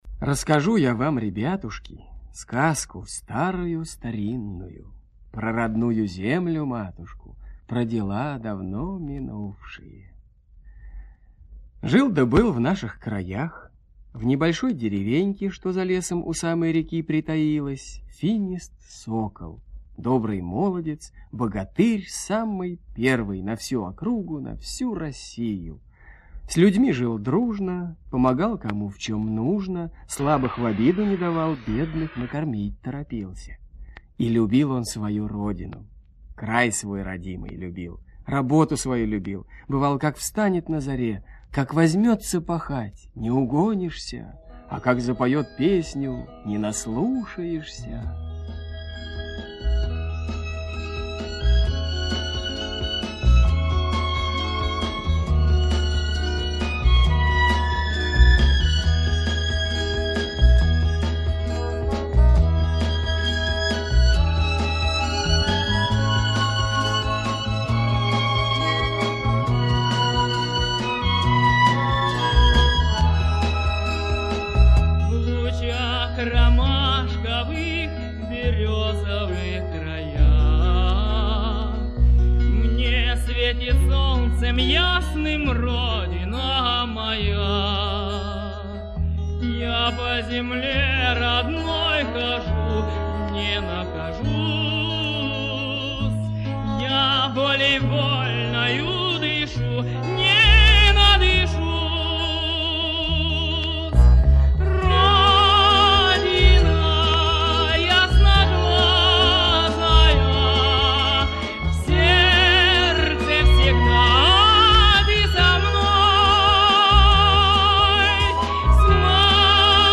Финист - ясный сокол - русская народная аудиосказка